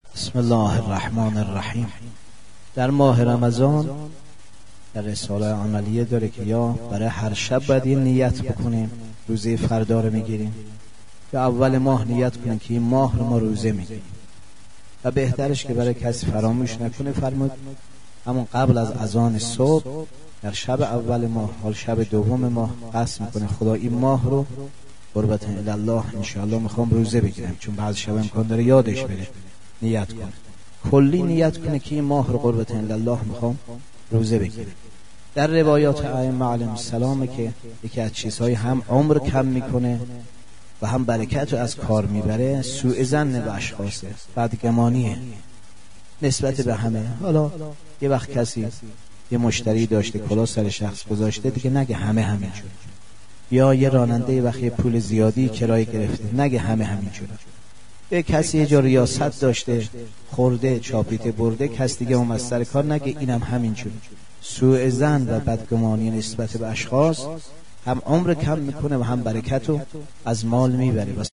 آرشیو ماه مبارک رمضان - سخنرانی - بخش اول